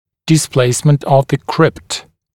[dɪs’pleɪsmənt əv ðə krɪpt][дис’плэйсмэнт ов зэ крипт]неправильное положение крипты